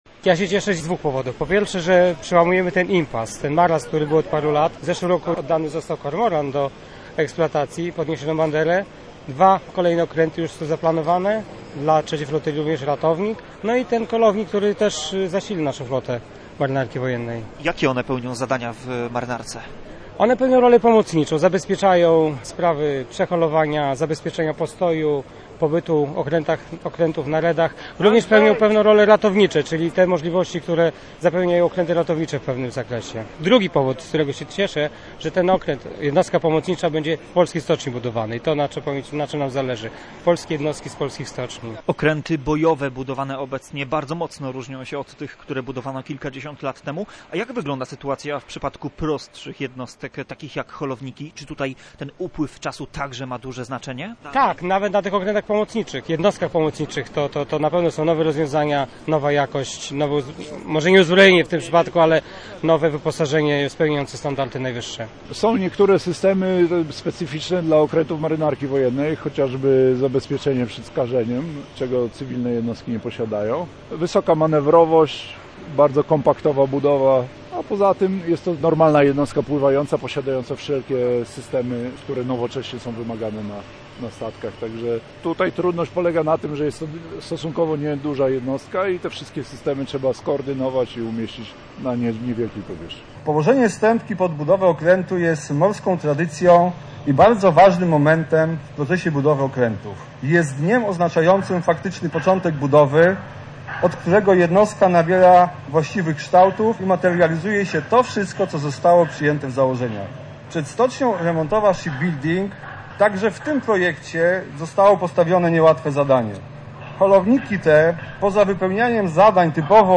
W uroczystości uczestniczył nasz reporter.